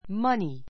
money 中 A1 mʌ́ni マ ニ 名詞 お金 , 金銭; 財産 ✓ POINT 硬貨 こうか や紙幣 しへい を含 ふく めた金銭一般に対する言い方.